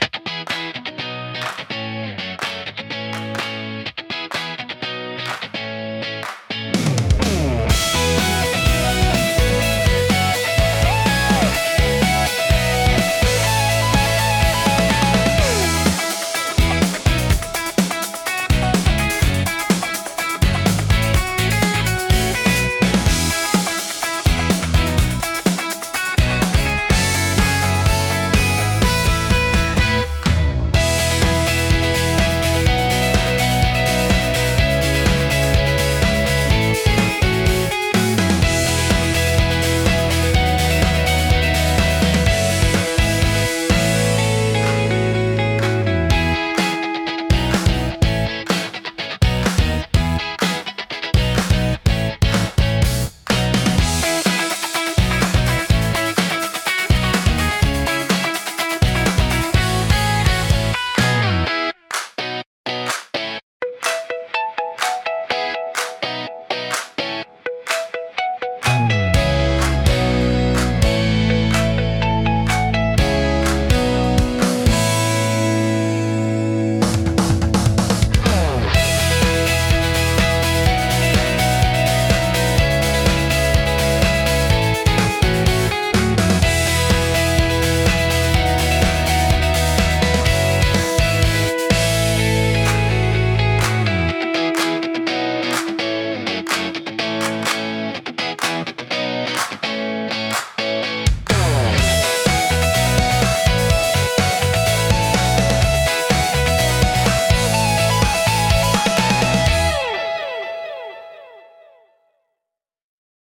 感情の高まりやポジティブな気分を引き出しつつ、テンポ良く軽快なシーンを盛り上げる用途が多いです。